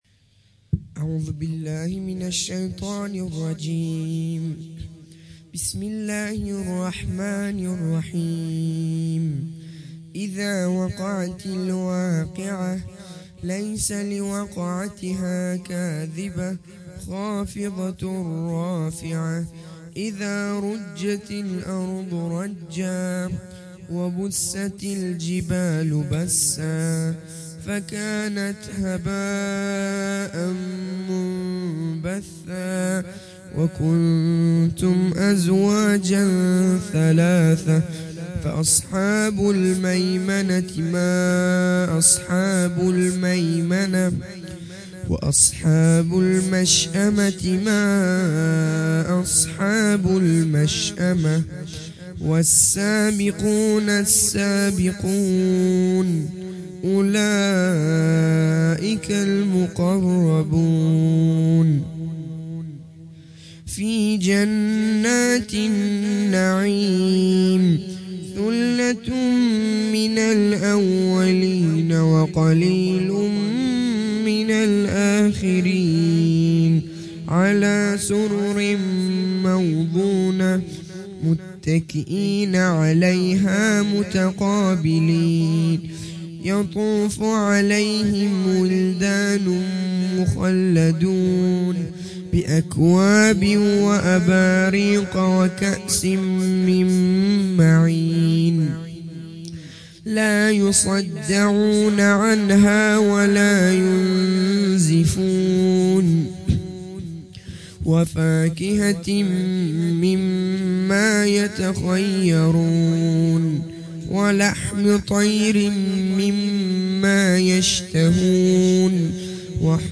قرائت سوره واقعه